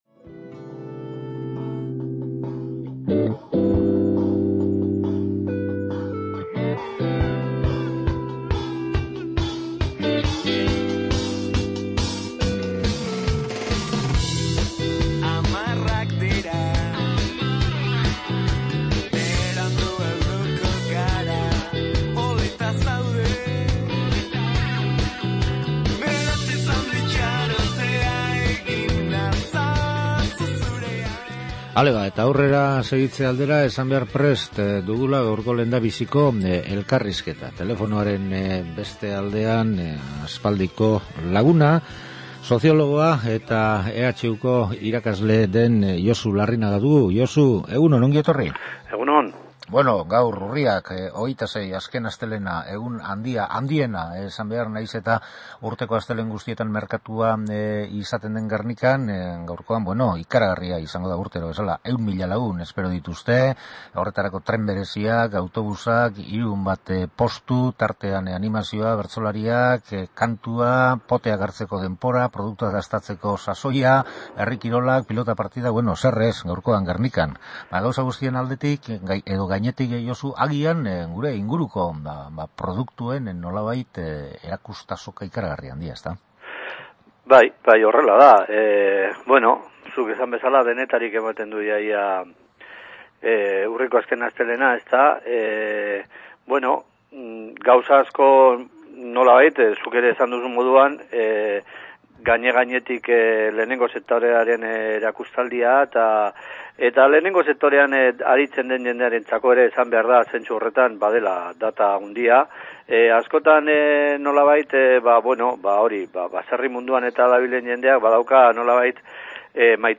solasaldia
Gure baserritarren kezka eta arrangurak, egungo arazorik larrienak, gazteen etorkizuna baserrian, soluziobideak… Guzti hori Gernikako azokatik datozkigun usai eta zaporeen artetik!